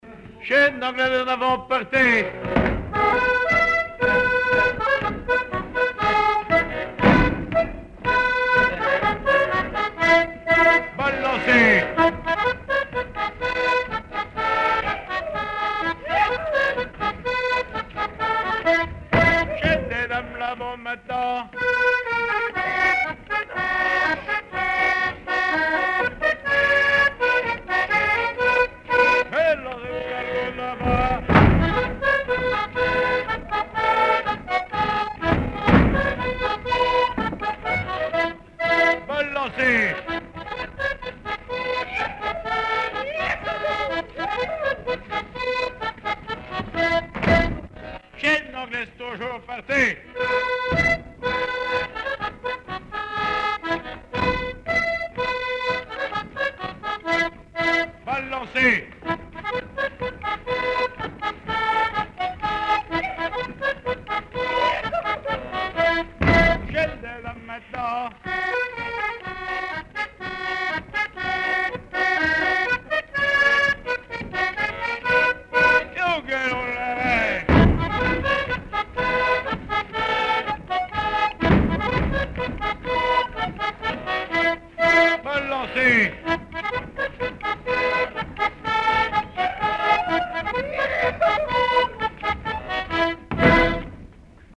danse : quadrille : chaîne anglaise
Répertoire du musicien sur accordéon chromatique
Pièce musicale inédite